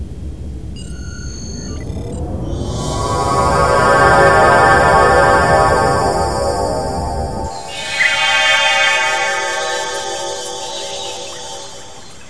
(The sound of transporter device)